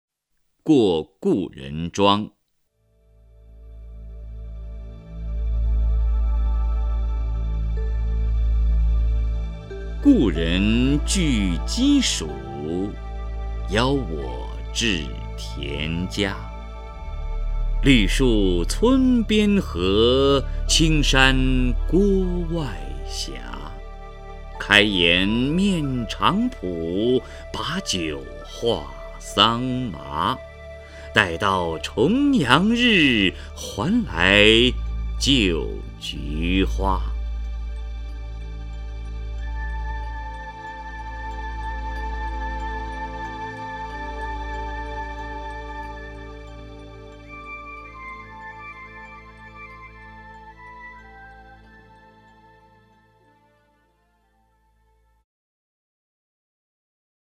王波朗诵：《过故人庄》(（唐）孟浩然) （唐）孟浩然 名家朗诵欣赏王波 语文PLUS